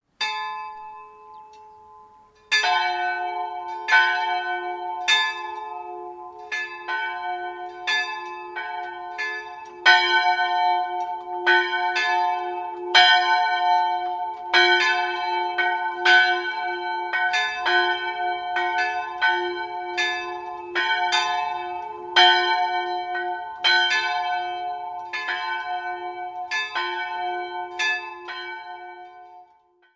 3-stimmiges Kleine-Terz-Geläute: fis''-a''
Zwei liebliche Glocken, die noch von Hand gezogen werden. Leider lässt die Qualität des Läutens in der Aufnahme ziemlich zu wünschen übrig.